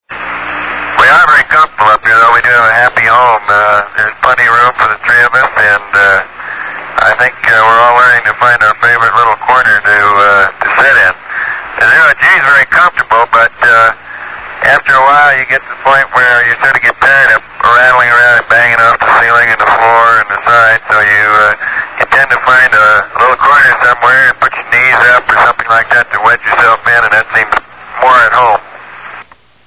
Tags: ORIGINAL COMMUNICATIONS APOLLO MISSIONS NASA